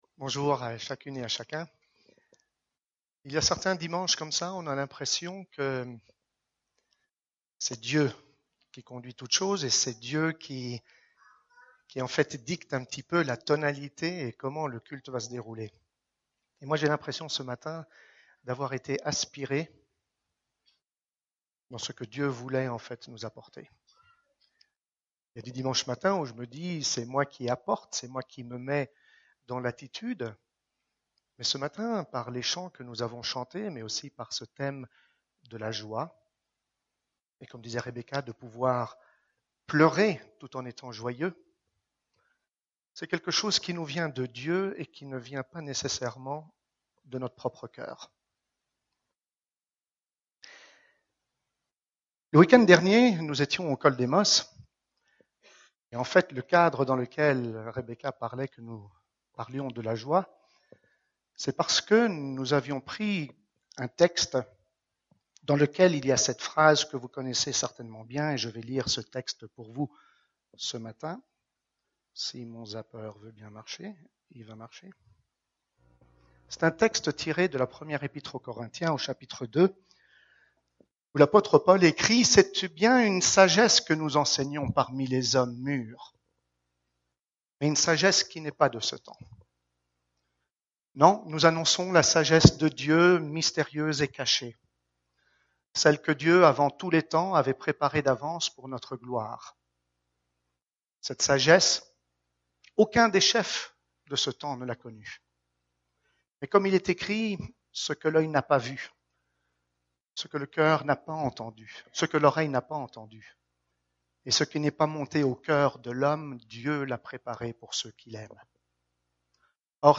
Culte du 20 septembre